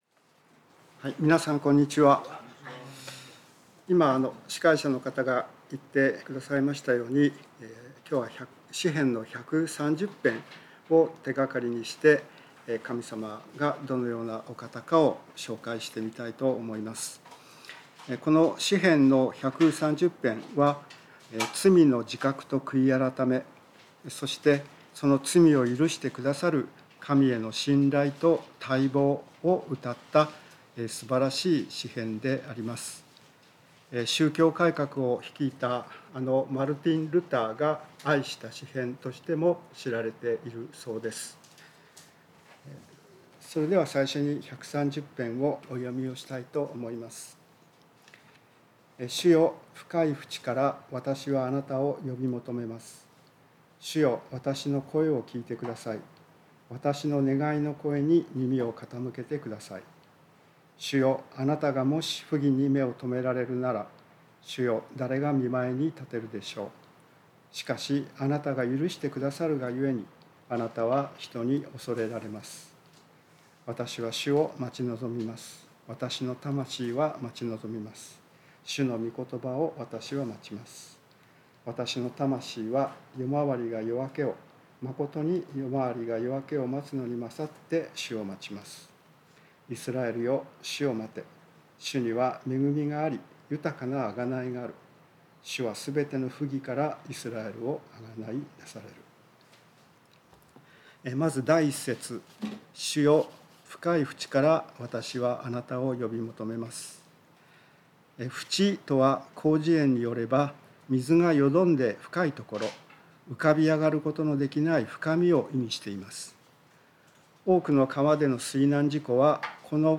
聖書メッセージ No.279